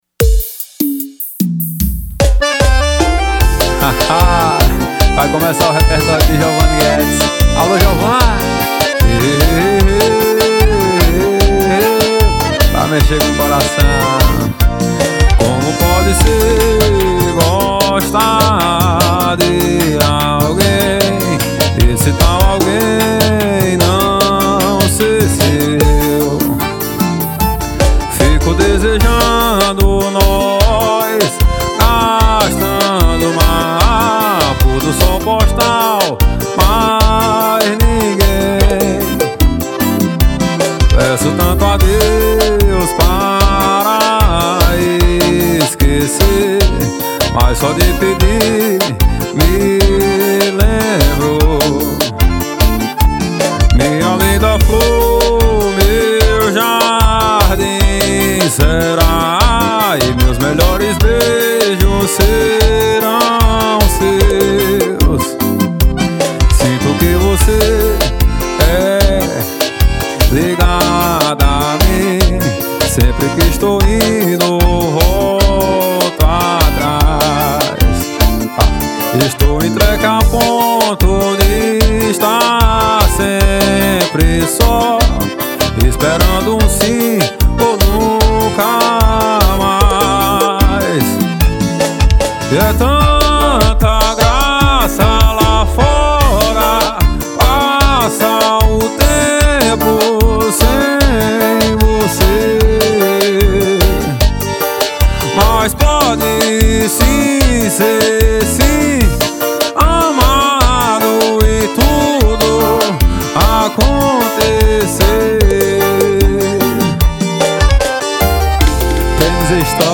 2024-02-14 17:57:24 Gênero: Forró Views